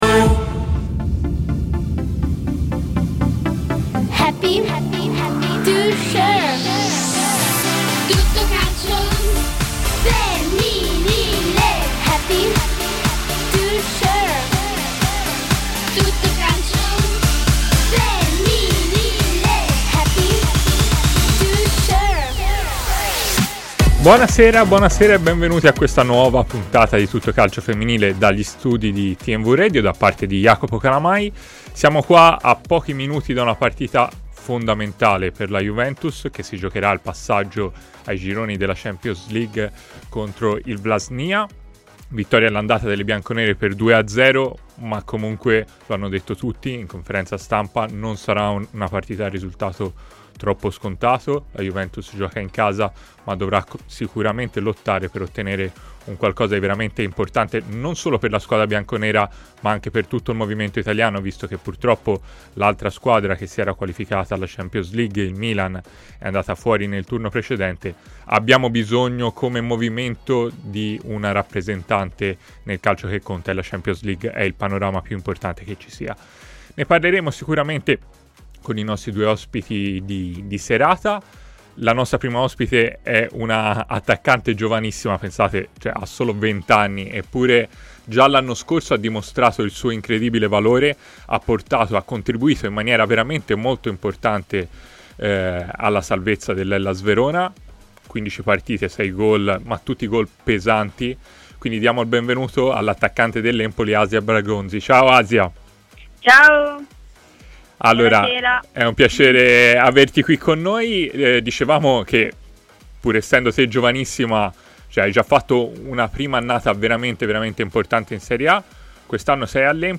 L'intervista integrale nel podcast